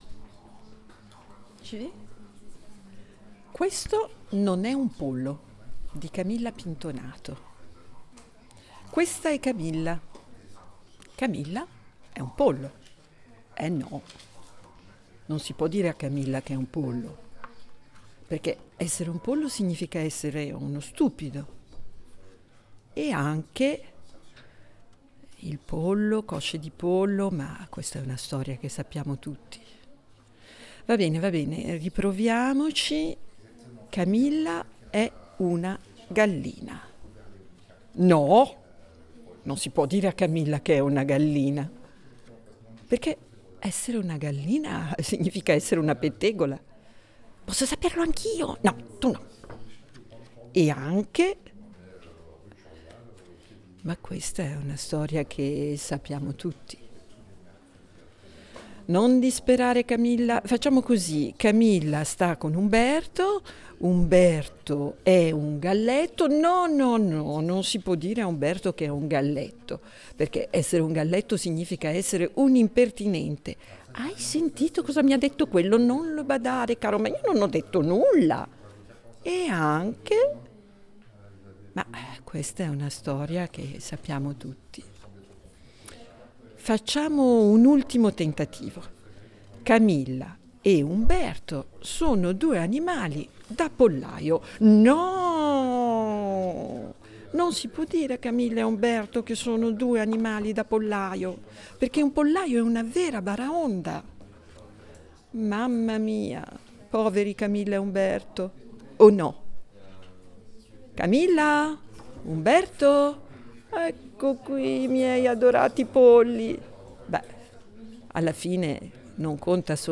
Cet espace invitait également à la flânerie, de nombreuses personnes se sont installées pour découvrir les albums et certaines nous ont fait le cadeau d’une lecture dans différentes langues.